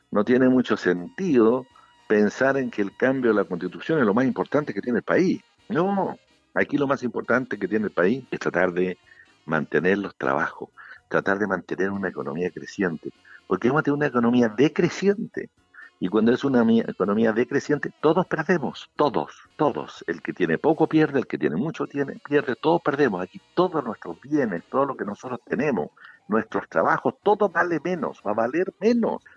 En entrevista con Radio Sago, el Intendente de la región de Los Lagos se refirió a la situación actual que vive el país en torno al coronavirus y los efectos que esta pandemia podría traer consigo en el futuro.